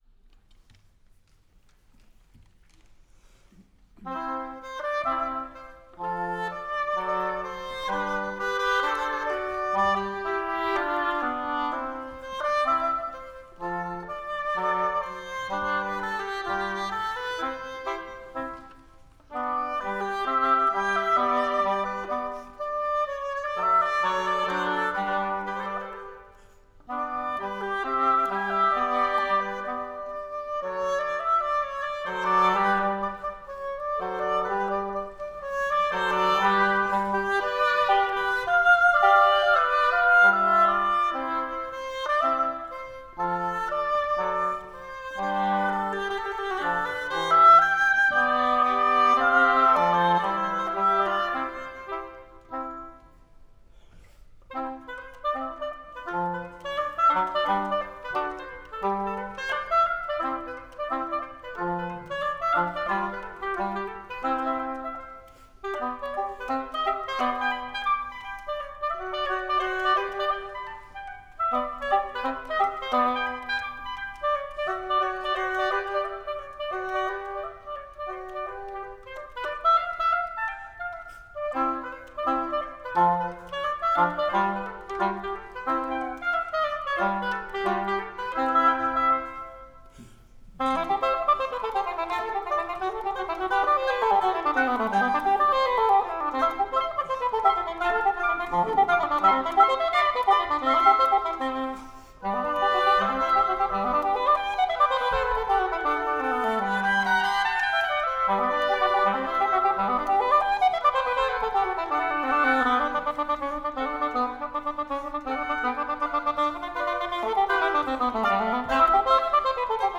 Hobo
trio voor 2 hobo’s en althobo
in de Nieuwe Kerk in Den Haag